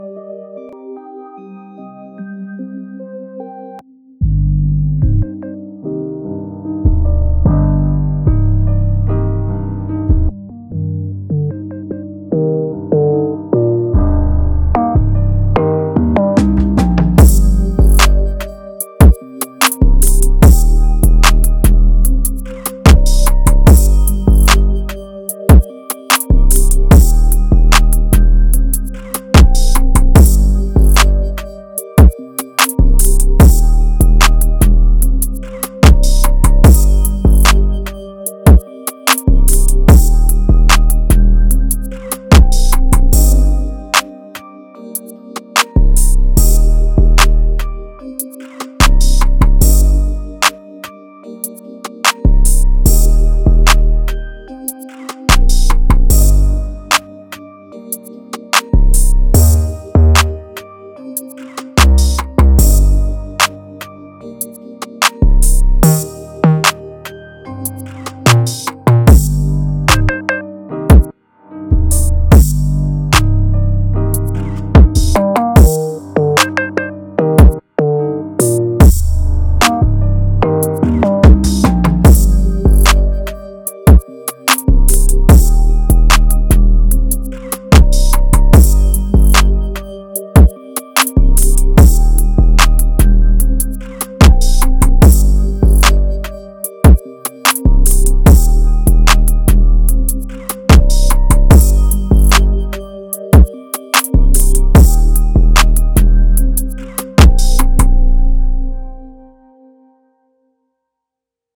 крутой бит с басами без авторских прав